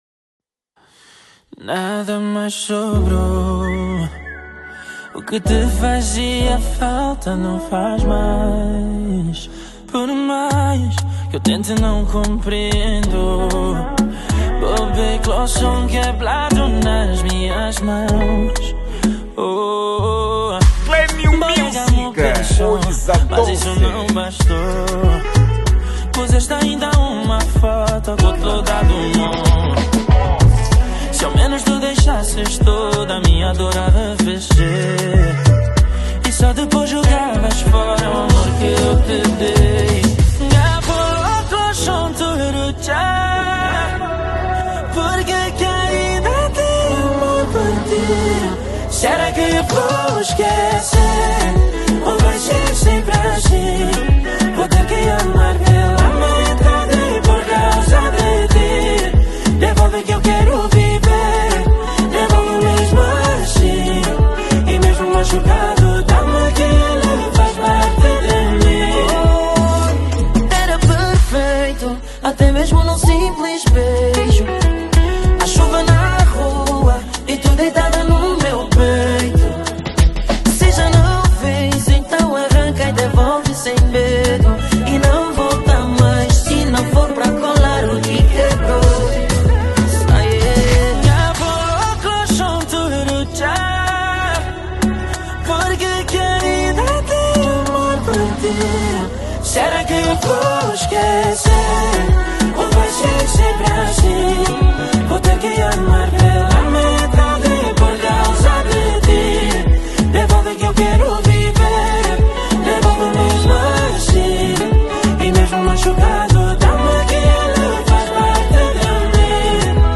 Género: Kizomba